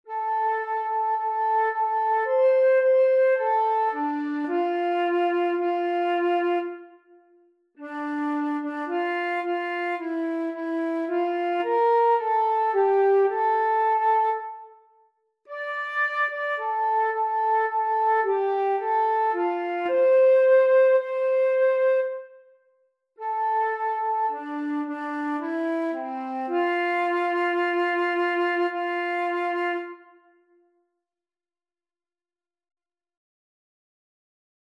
als vierstemmige canon